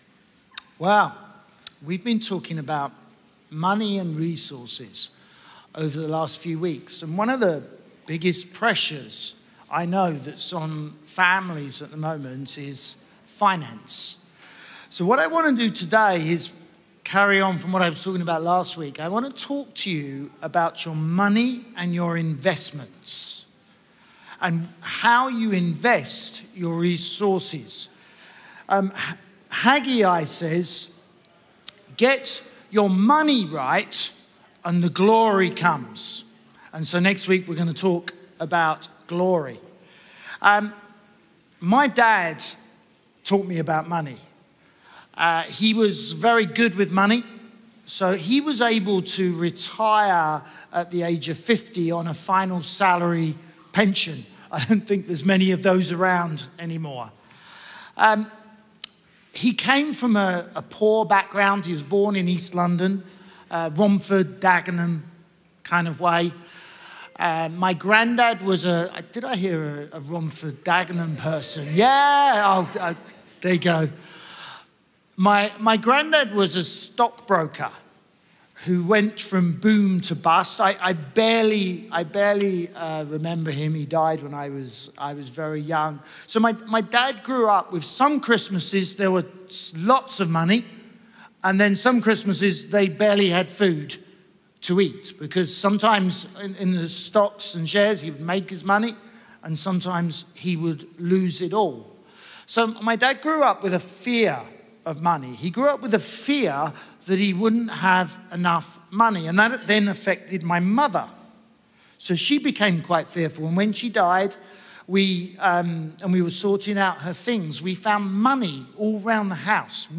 Chroma Church - Sunday Sermon Money - 3 Principles and 5 Investments Aug 23 2023 | 00:34:03 1x 00:00 / 00:34:03 Subscribe Share RSS Feed Share Link Embed